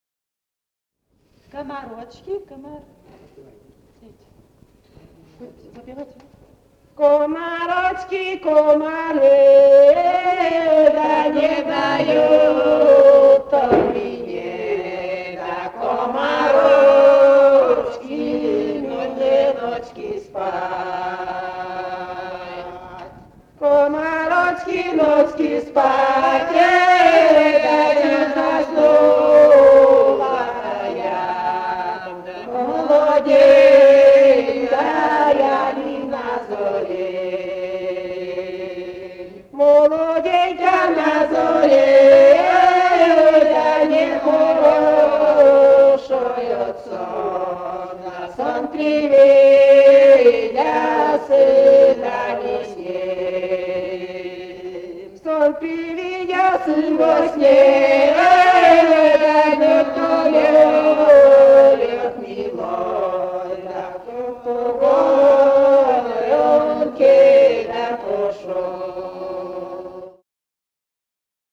Живые голоса прошлого [[Описание файла::020. «Комарочки, комары» (лирическая).